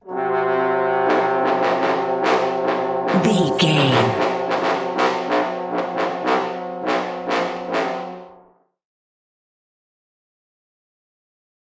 In-crescendo
Thriller
Atonal
tension
ominous
brass
synths
Horror Synths
atmospheres